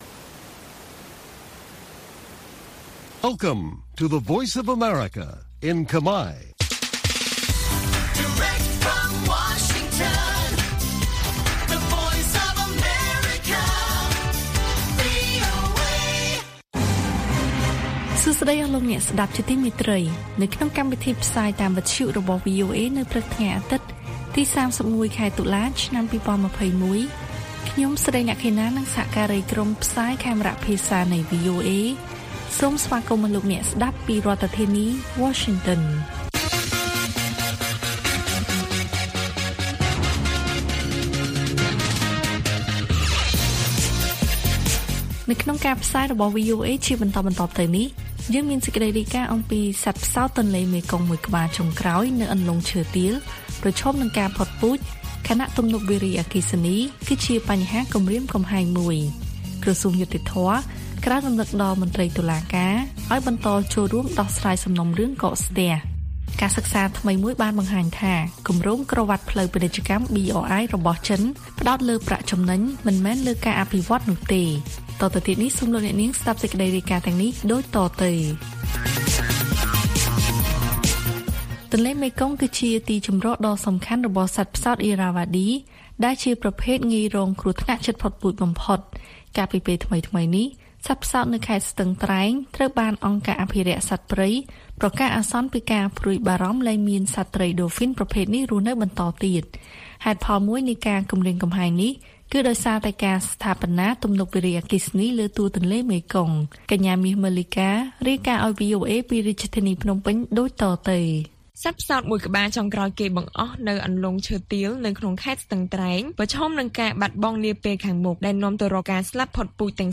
ព័ត៌មានពេលព្រឹក៖ ៣១ តុលា ២០២១